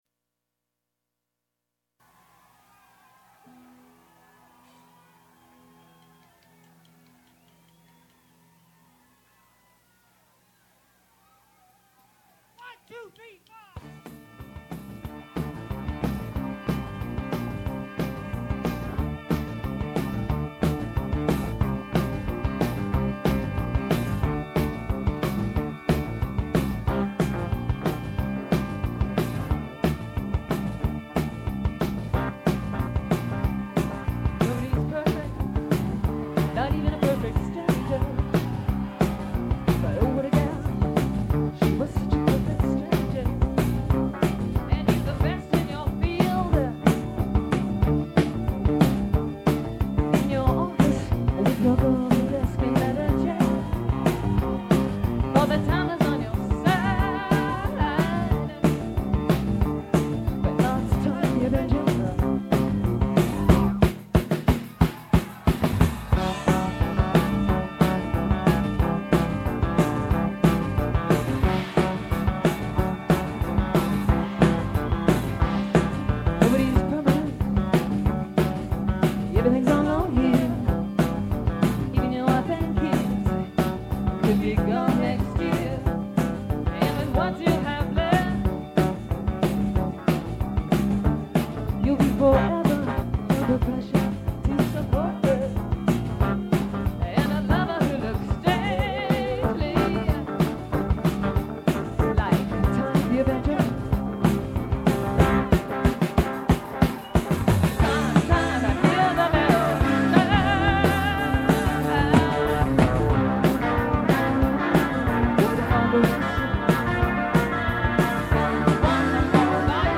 Listen to the warm-up set here.